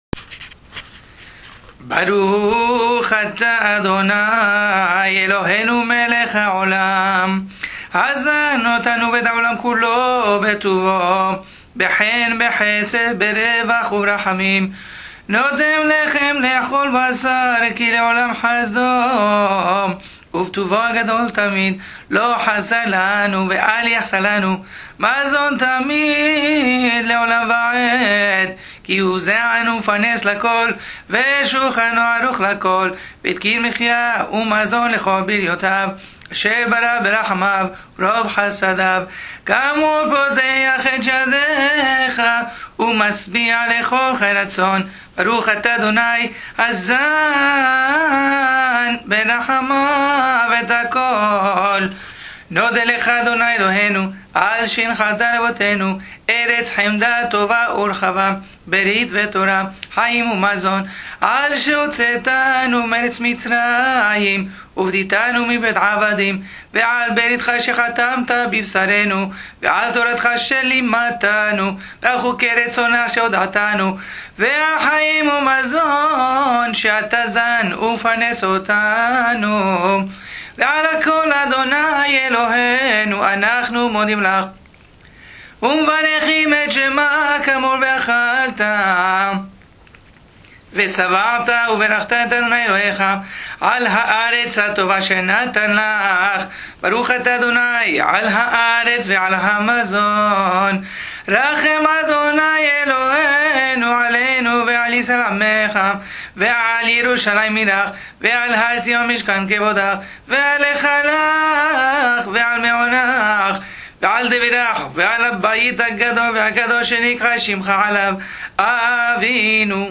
All recordings feature traditional melodies and pronunciation as used in our daily services.